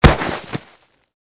bodyfalldirt10.mp3